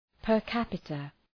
Προφορά
{pər’kæpıtə}